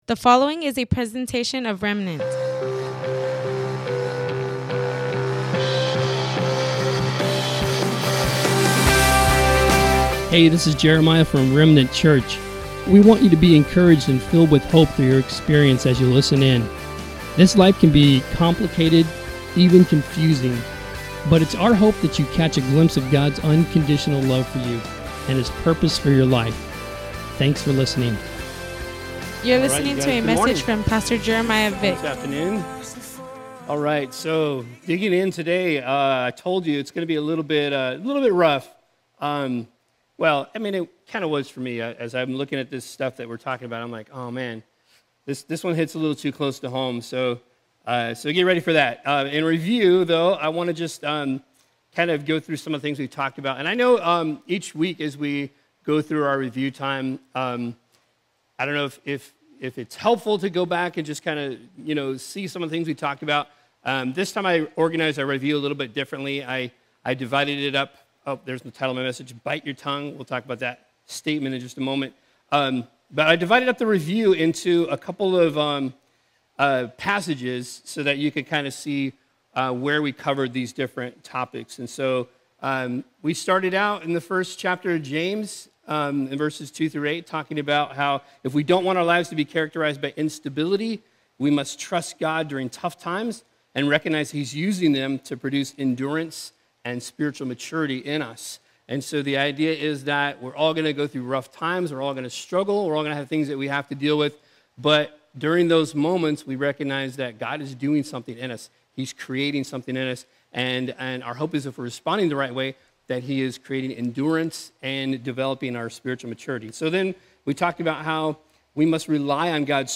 Welcome to the livestream of our worship gathering at Remnant Church in Imperial Valley, CA. Today